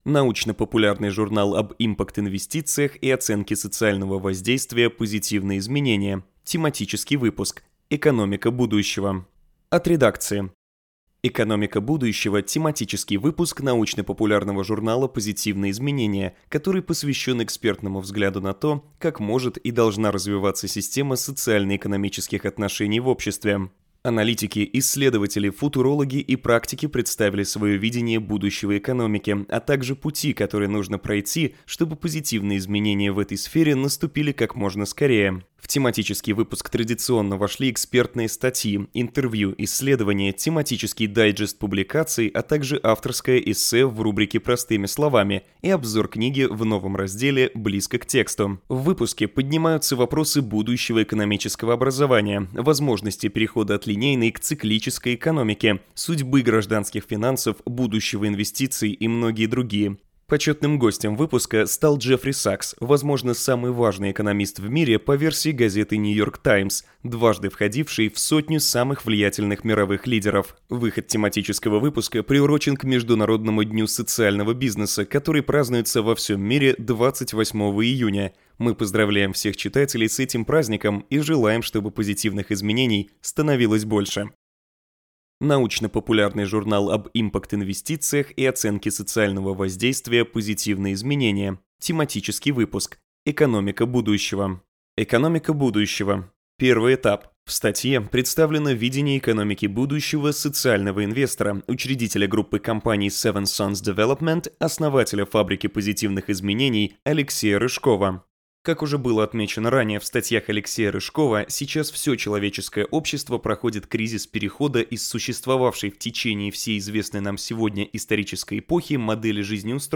Аудиокнига Позитивные изменения. Тематический выпуск «Экономика будущего» (2023).